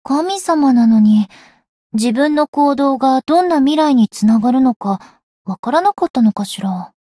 灵魂潮汐-星见亚砂-七夕（相伴语音）.ogg